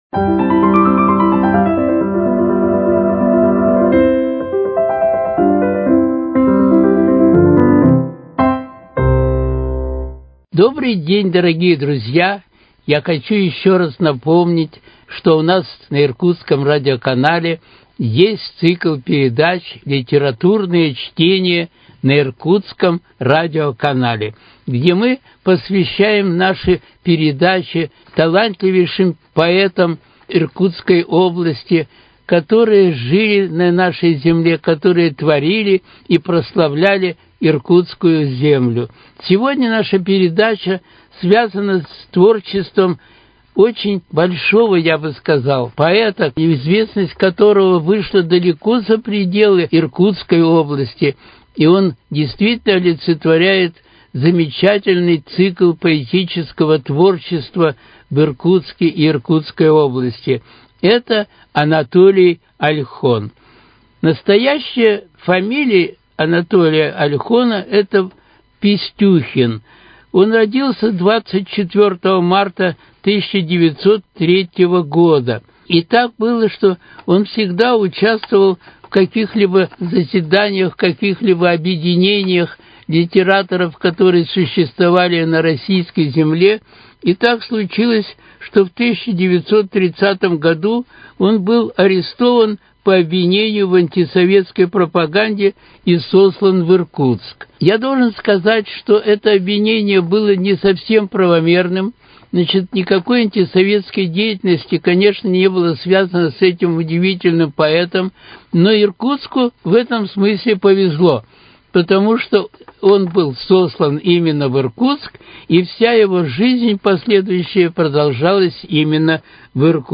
читает в эфире произведения классиков. В этом выпуске – стихи поэта Анатолия Ольхона.